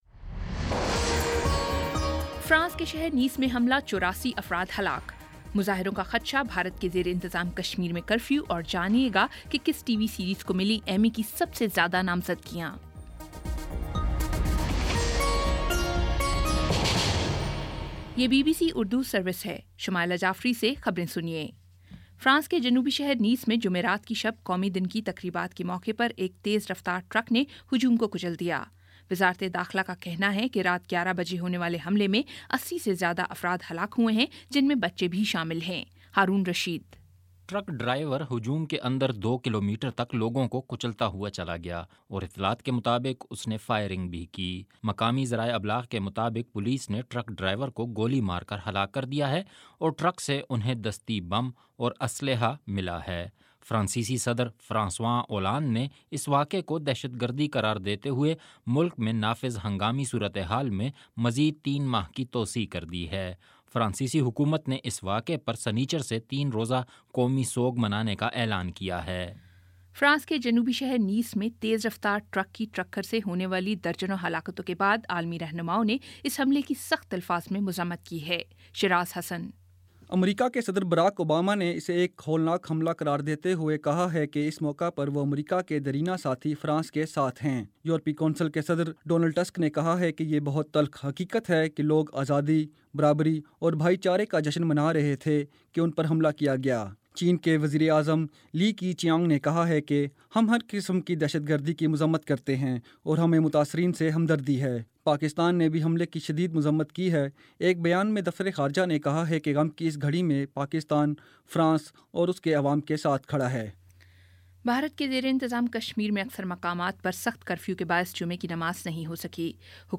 جولائی 15 : شام پانچ بجے کا نیوز بُلیٹن